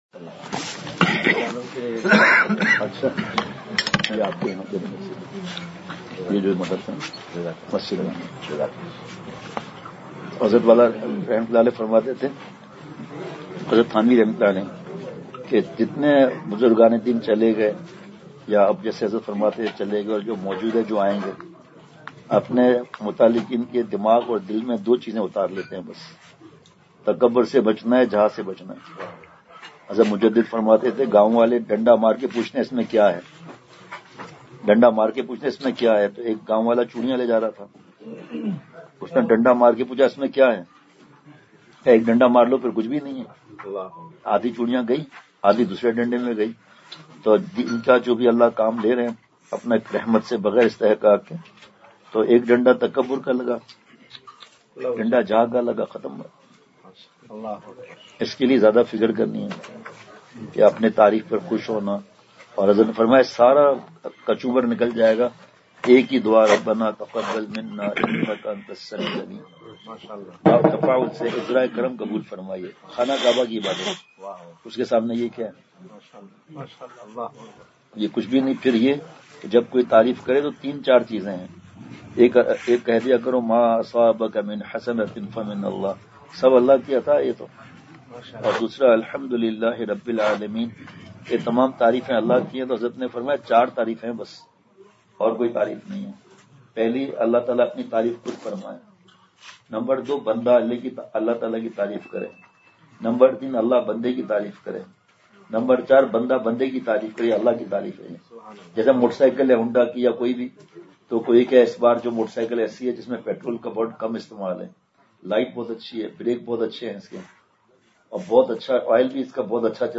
Please download the file: audio/mpeg مجلس محفوظ کیجئے اصلاحی مجلس کی جھلکیاں بمقام :۔ جامع مسجد برھان خیل بڈھ بیر پشاور